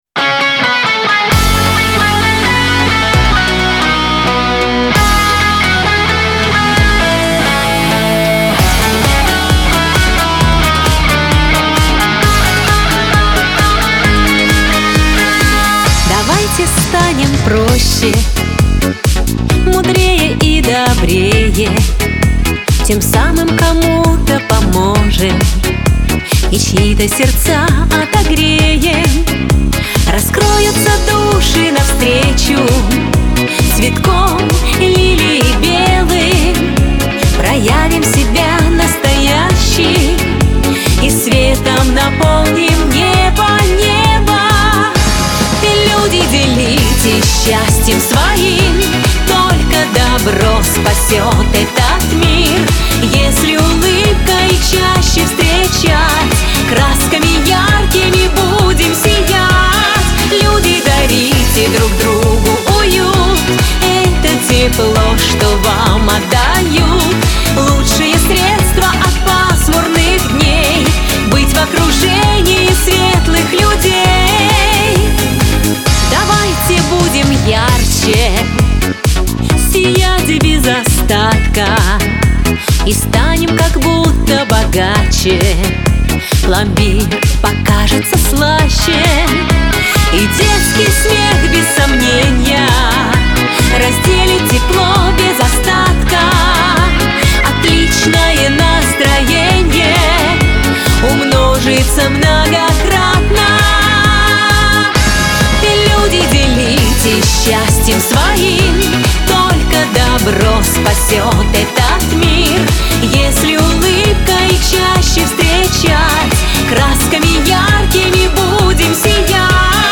pop , эстрада
диско